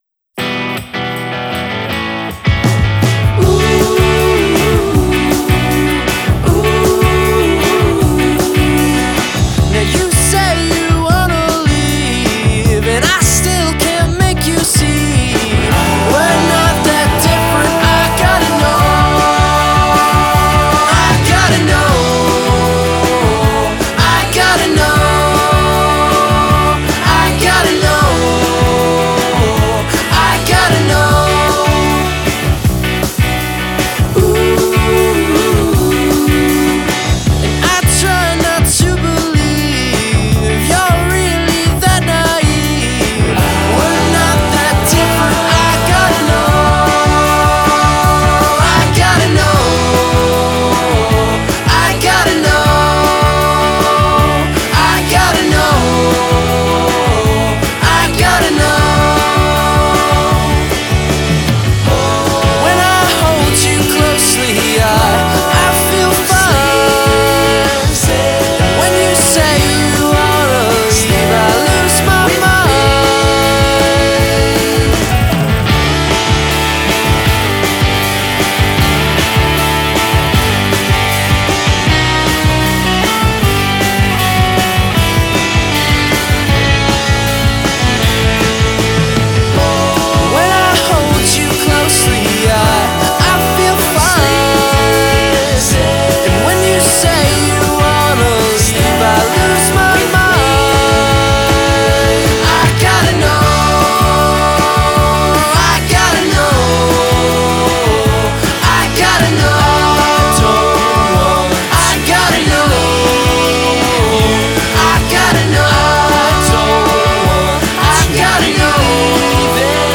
sweet vocal harmonies and melody-driven tune-age
spare guitar work and layered vocals